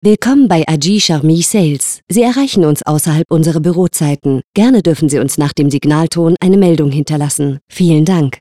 Telefonansage